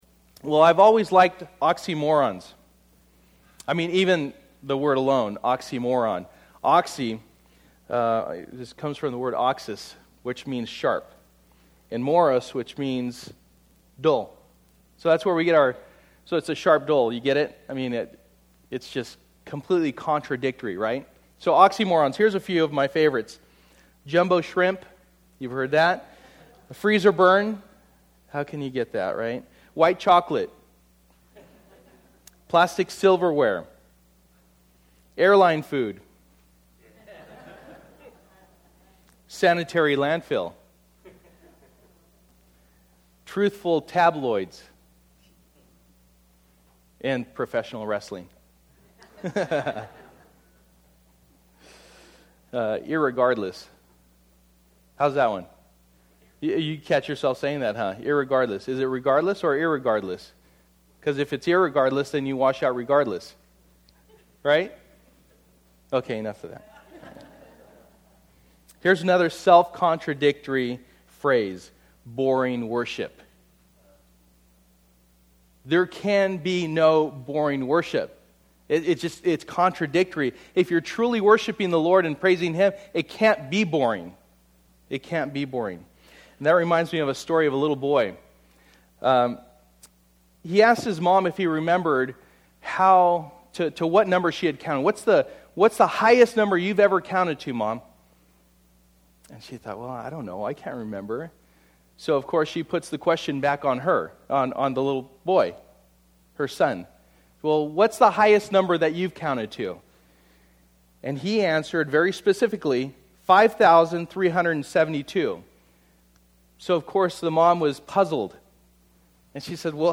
Passage: Psalm 145:1-21 Service: Sunday Morning